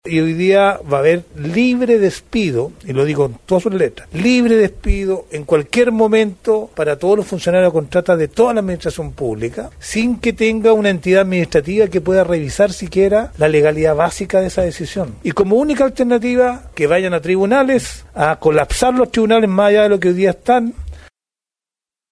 El diputado socialista, Leonardo Soto, autor de esta iniciativa, comentó que este proyecto viene a resolver un vacío legal.